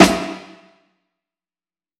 Plattenhall
Plattenhall.wav